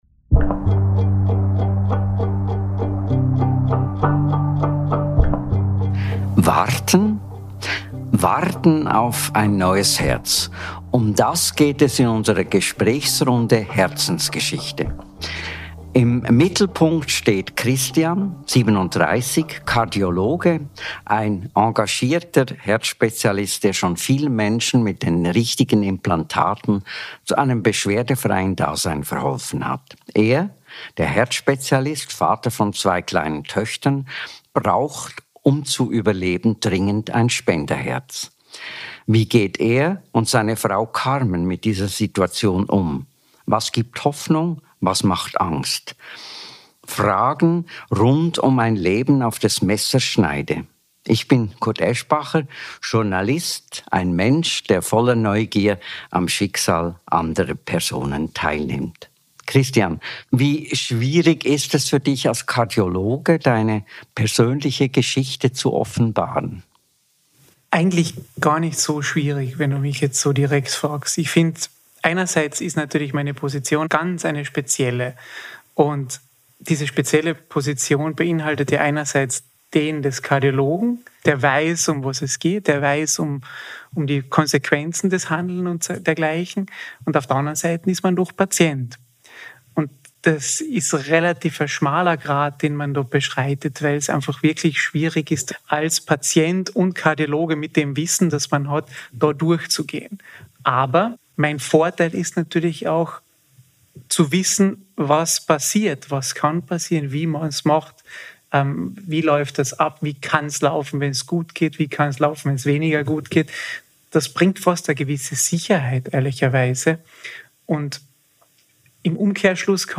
Gemeinsam mit Kurt Aeschbacher spricht er in der ersten Folge von "Herzensgeschichte" über die Zeit des Wartens – über Angst, Hoffnung und den Blick auf das eigene Leben zwischen Arztberuf und Patientendasein.